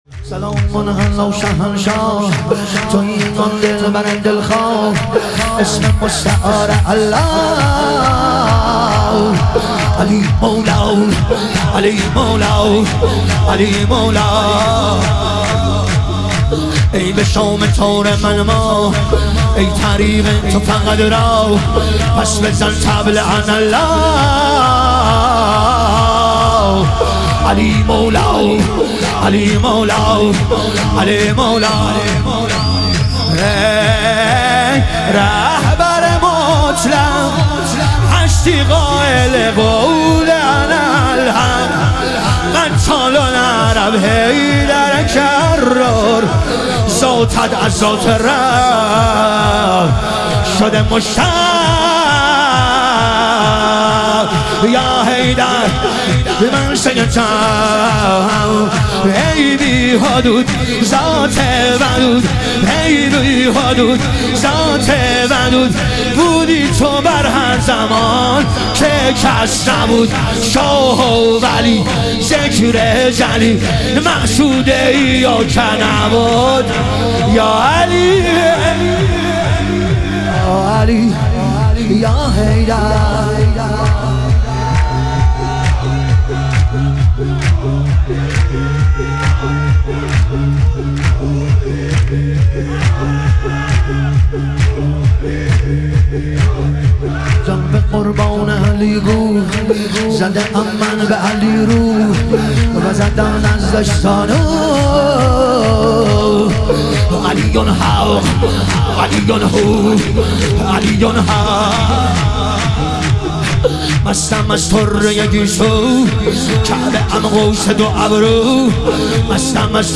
شور
شب ظهور وجود مقدس حضرت قاسم علیه السلام